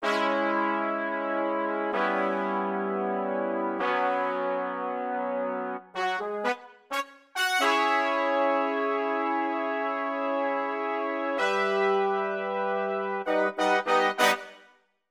14 brass 3 C1.wav